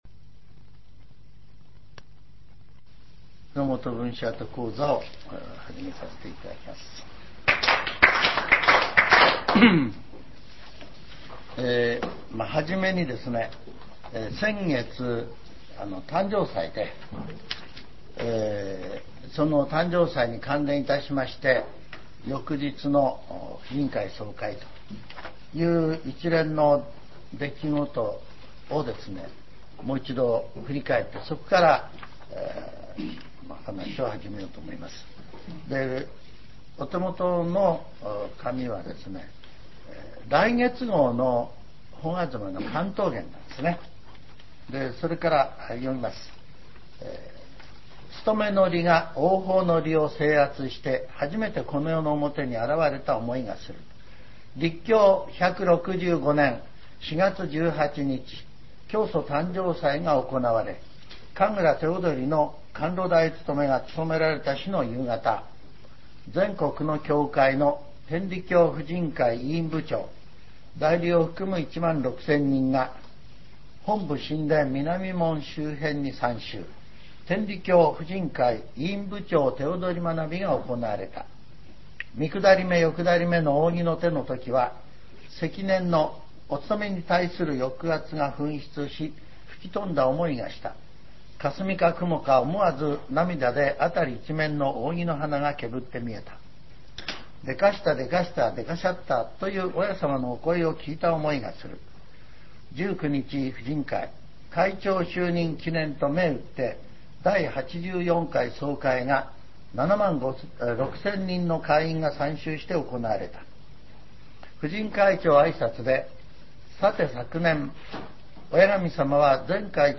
全70曲中9曲目 ジャンル: Speech